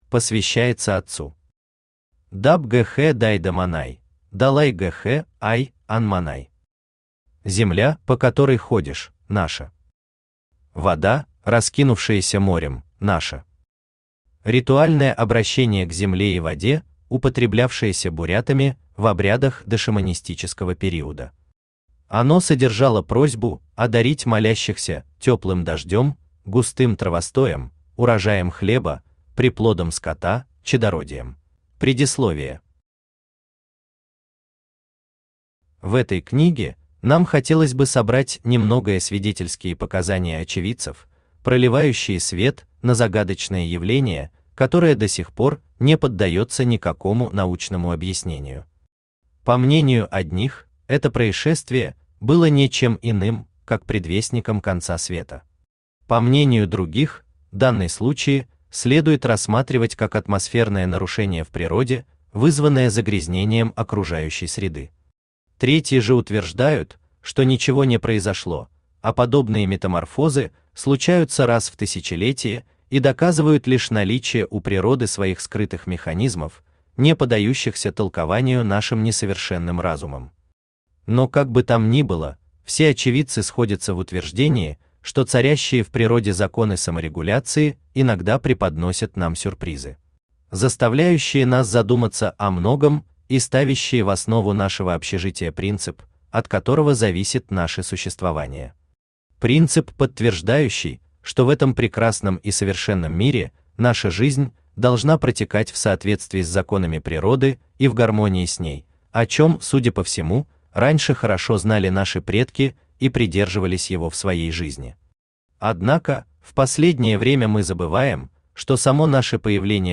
Аудиокнига Земля Обетованная | Библиотека аудиокниг
Aудиокнига Земля Обетованная Автор Владимир Фёдорович Власов Читает аудиокнигу Авточтец ЛитРес.